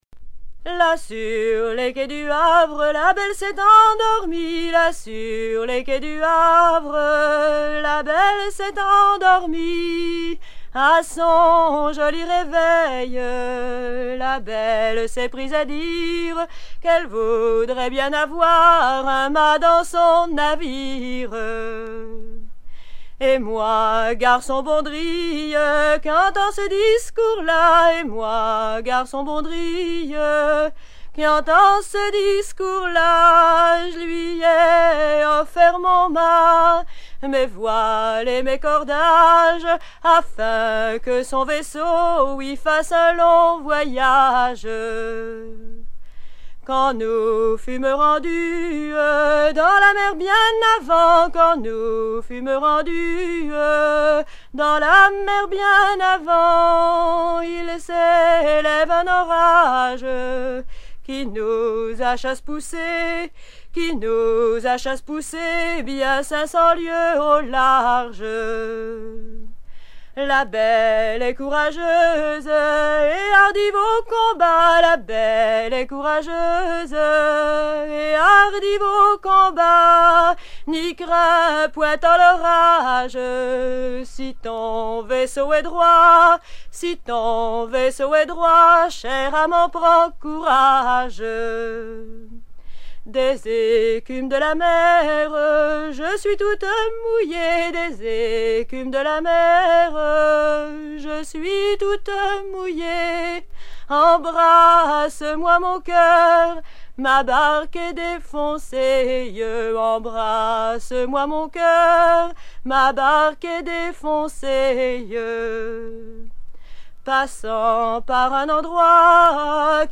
Version recueillie en 1977
Genre laisse
Pièce musicale éditée